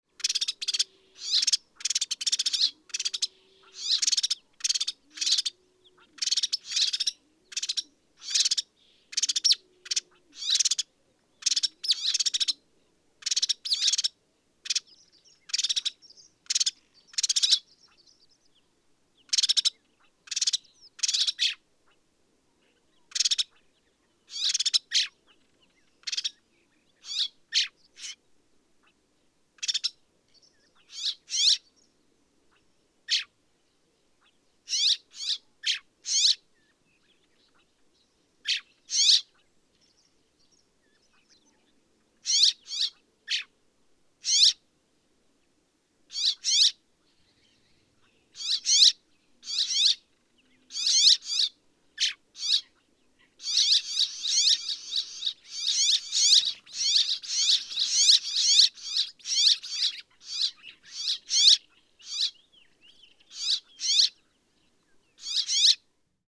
Family business (several individuals, one species)
Common Rock Sparrow Petronia petronia brevirostris, flock, song, excitement calls